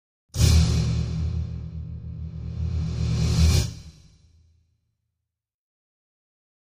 Drum Metallic Hit Reversed - Final Hits - A